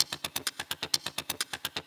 Index of /VEE/VEE2 Loops 128BPM
VEE2 Electro Loop 017.wav